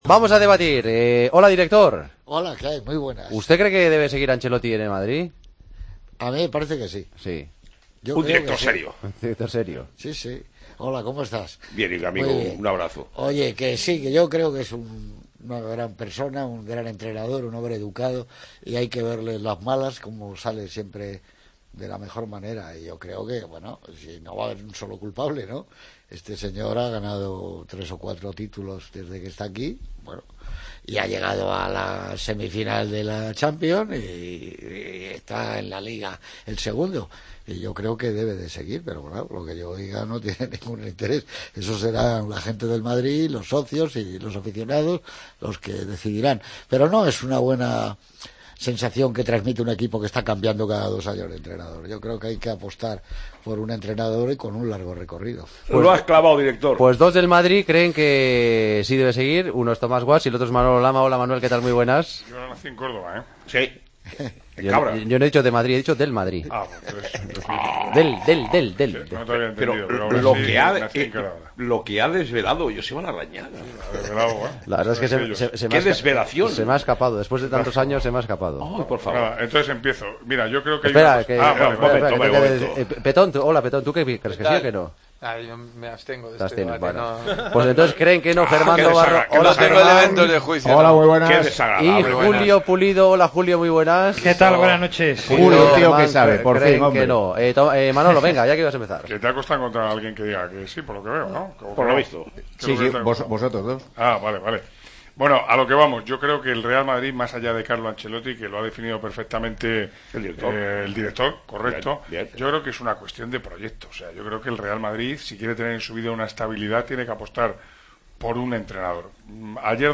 El debate de los jueves: ¿Debe seguir Ancelotti en el Real Madrid?